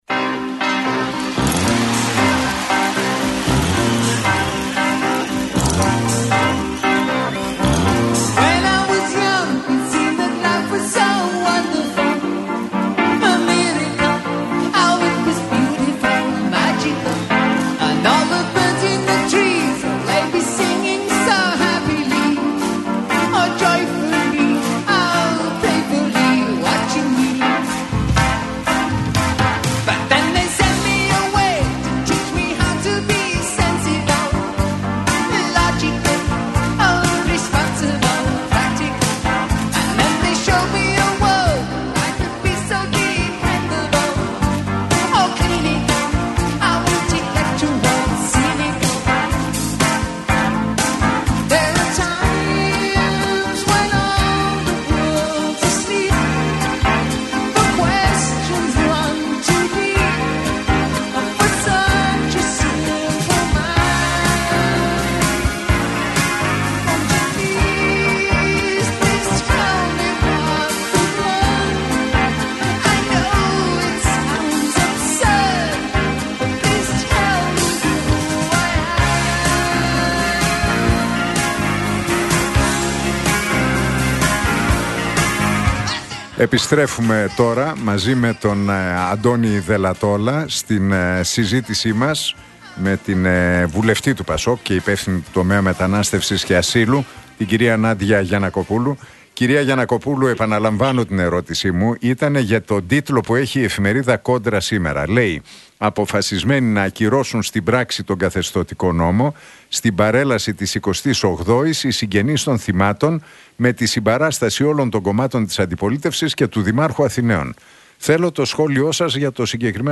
Ακούστε την εκπομπή του Νίκου Χατζηνικολάου στον ραδιοφωνικό σταθμό RealFm 97,8, την Πέμπτη 23 Οκτώβρη 2025.